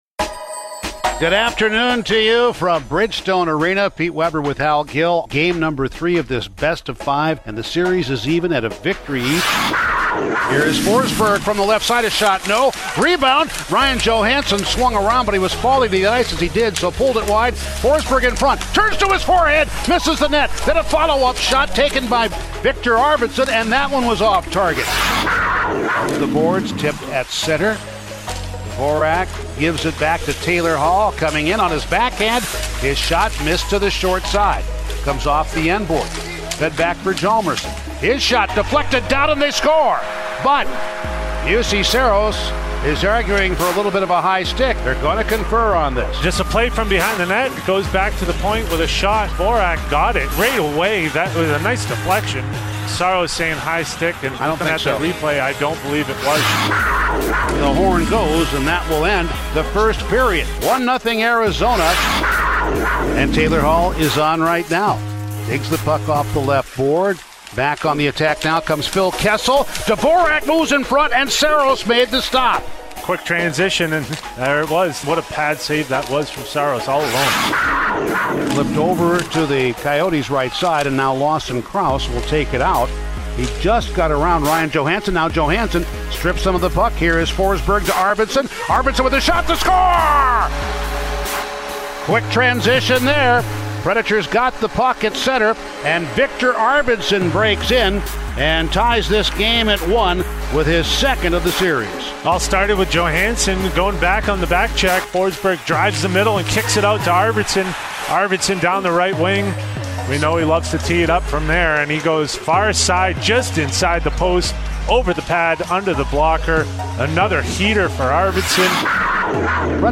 Full radio highlights from the Nashville Predators 4-1 loss to the Coyotes in Game 3 of the Qualifying Round on August 5, 2020, as heard on the Nashville Predators Radio Network